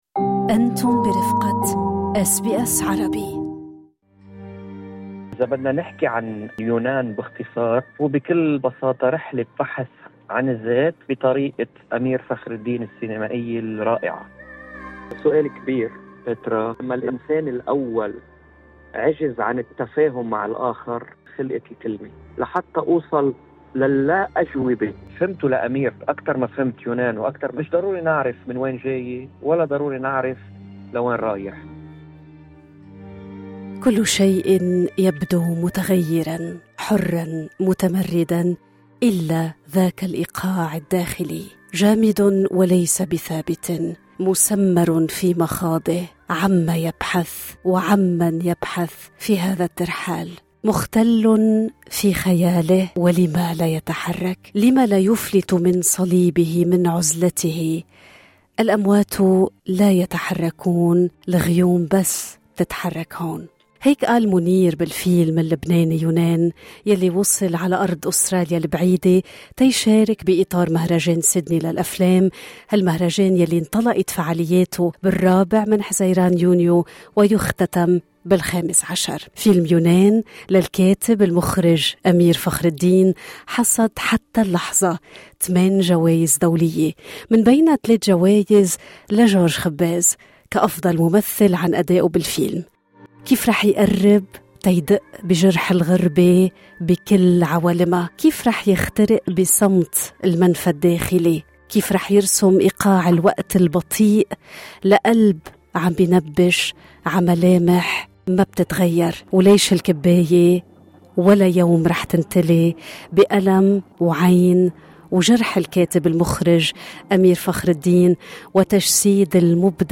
Award-winning acclaimed Lebanese actor George Khabbaz who received his third best actor award for his performance in the film "Younan" directed by Ameer Fakher Eldin, featured at the Sydney Film Festival, unveils his journey of self discovery in an in-depth talk